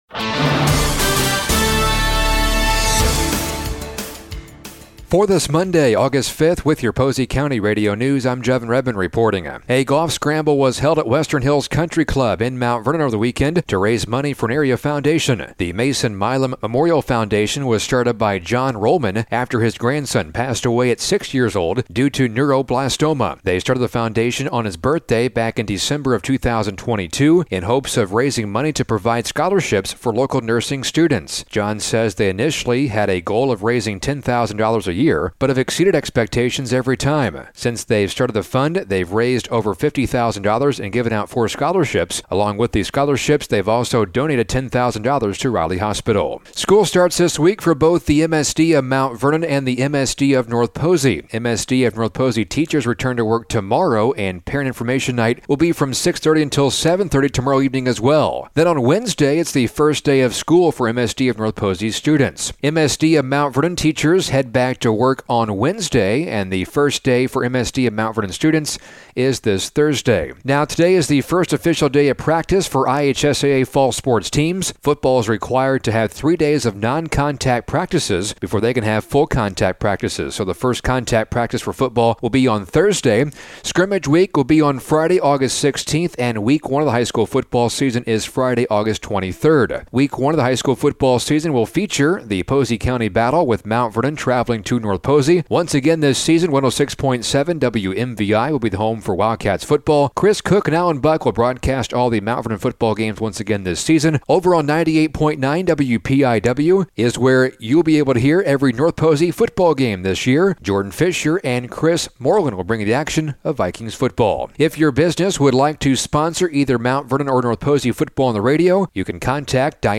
Local News: Monday August 5th 2024